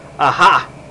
Ah Ha Sound Effect
Download a high-quality ah ha sound effect.
ah-ha.mp3